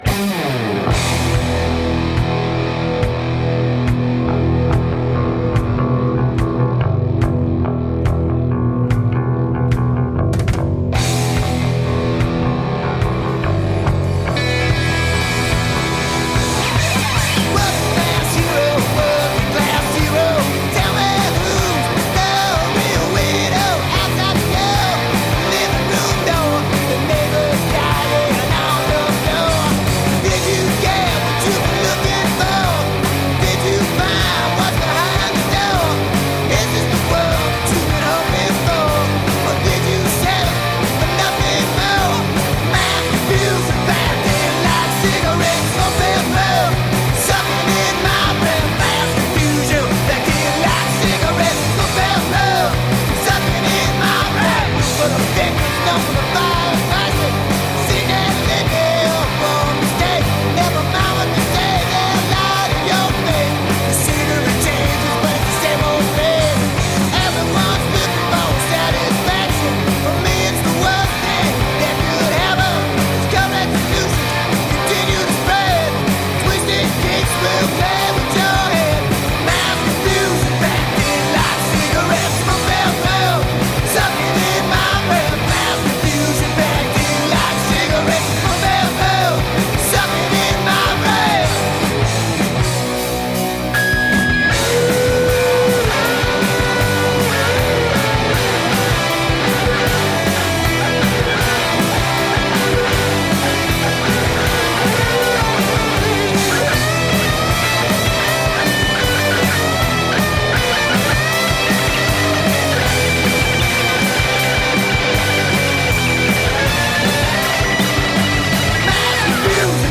High energy punk rock & roll.
Tagged as: Hard Rock, Punk, Rock, High Energy Rock and Roll